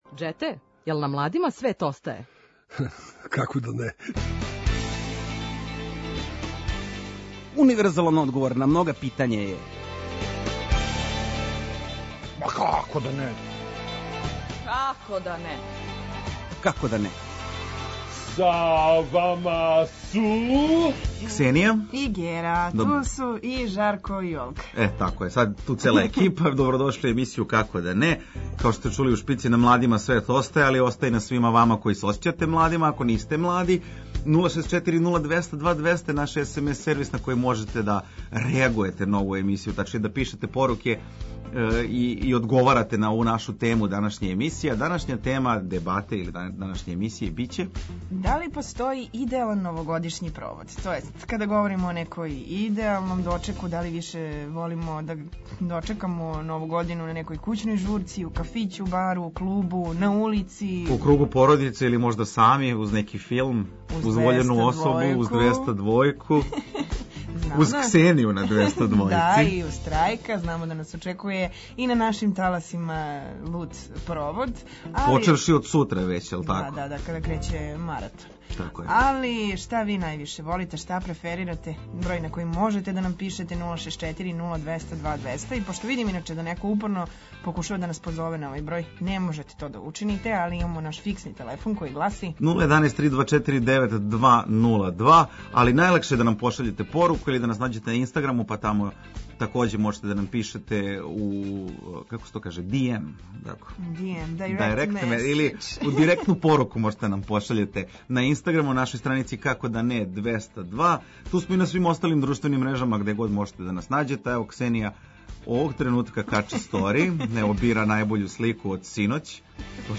Окосница емисије је „Дебата" у којој ћемо разменити мишљења о различитим темама и дилемама. Да ли постоји идеалан новогодишњи провод?
Наши „Гости вредни пажње" биће чланови састава „Артан Лили".